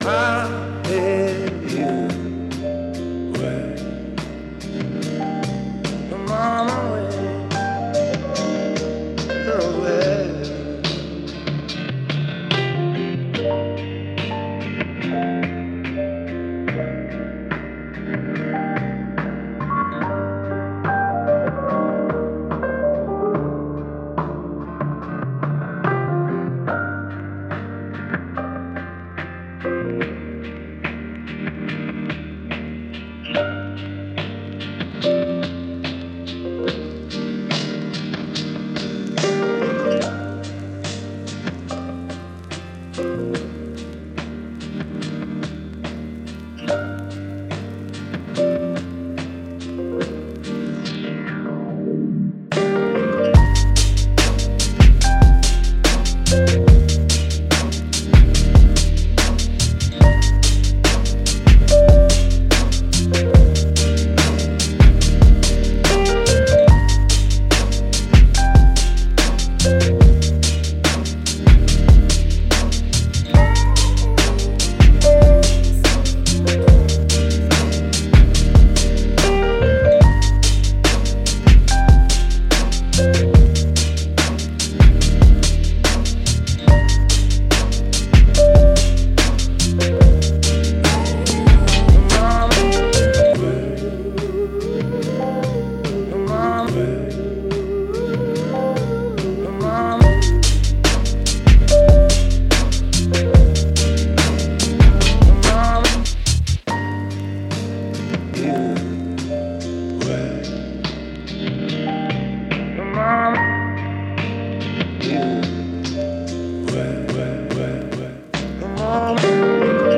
Жанр: Жанры / Хип-хоп